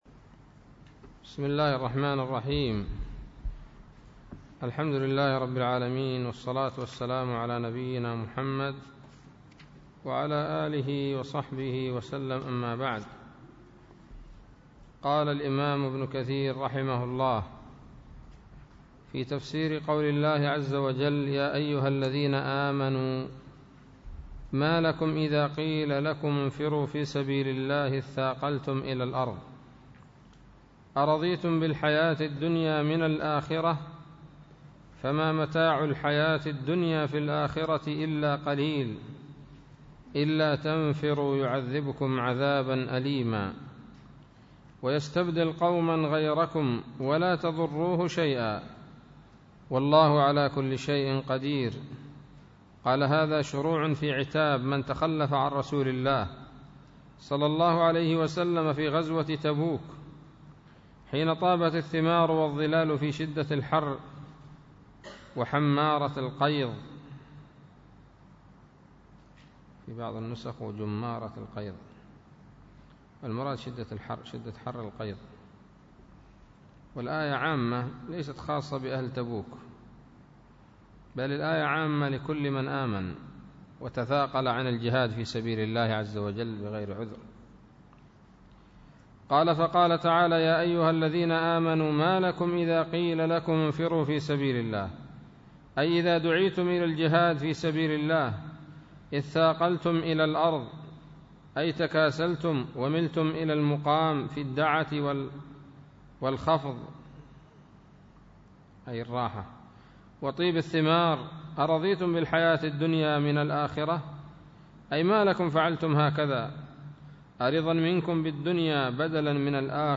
الدرس الثامن عشر من سورة التوبة من تفسير ابن كثير رحمه الله تعالى